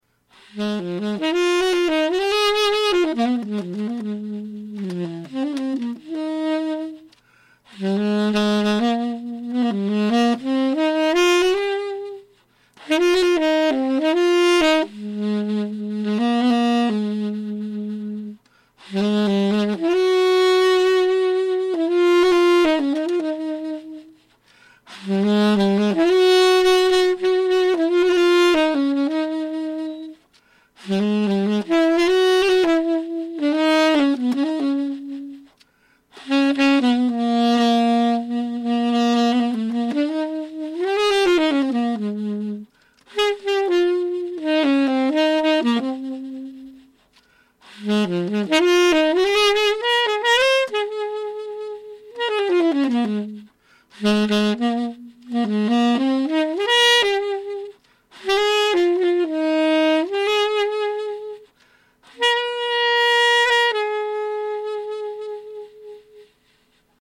Together with his Tenor and Soprano Saxophones. So live Christmas music this morning